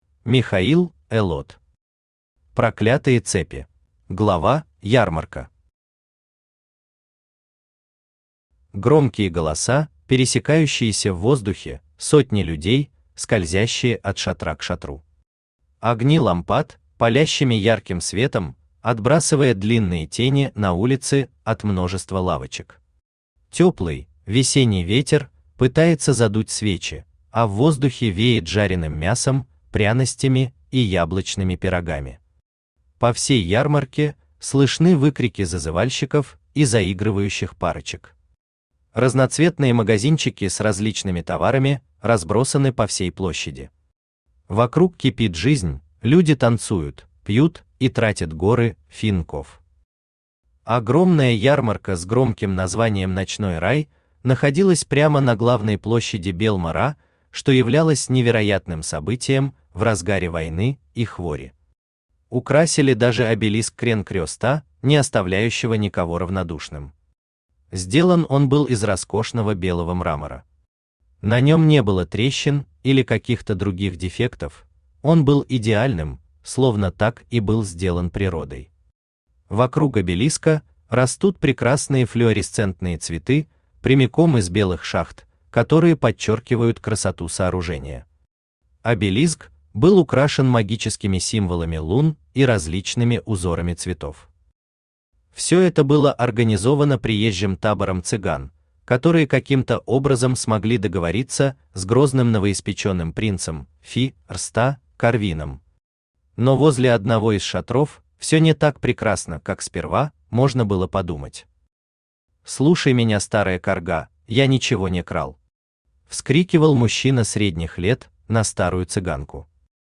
Аудиокнига Проклятые цепи | Библиотека аудиокниг
Aудиокнига Проклятые цепи Автор Михаил Элот Читает аудиокнигу Авточтец ЛитРес.